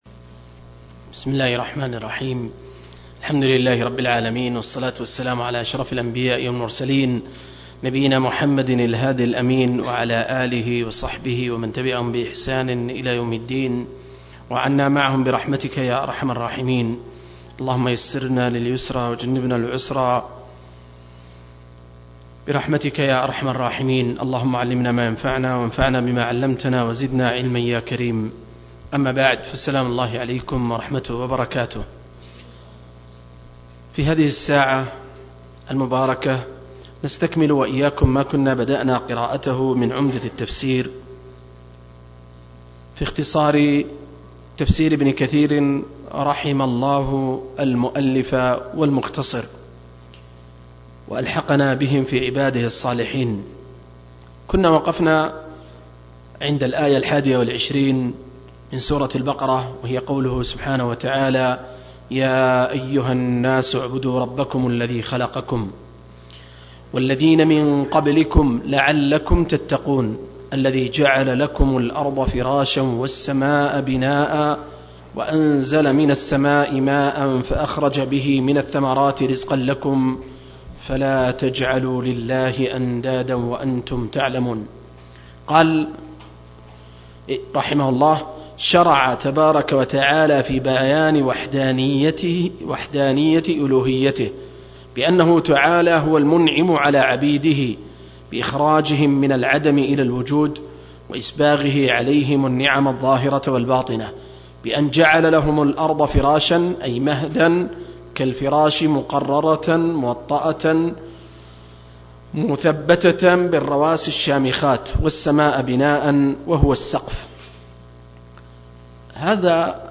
المجلس الحادي عشر: تفسير سورة البقرة (الآيات 21-24)